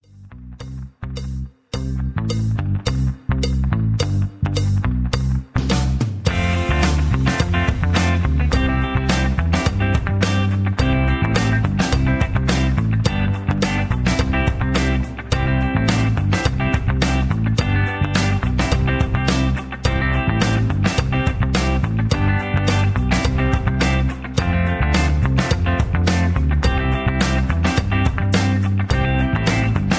backing tracks
country, southern rock